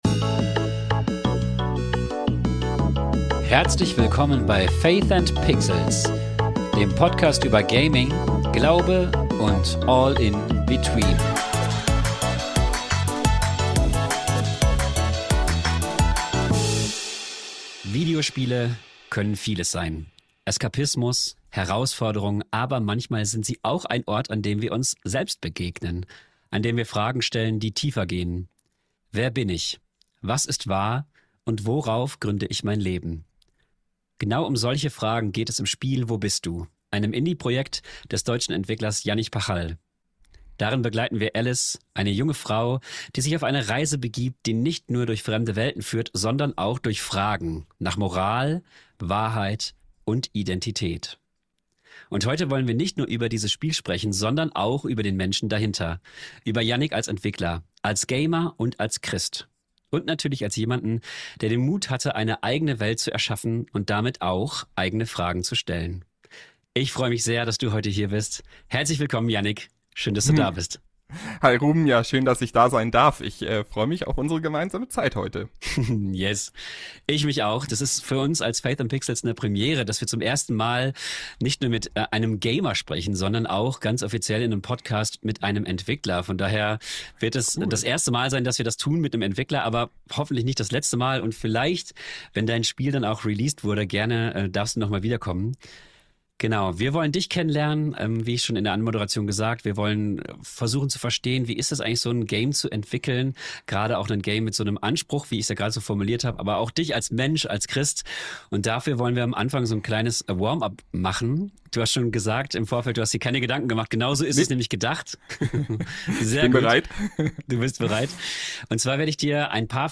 SPEZIALFOLGE - Im Interview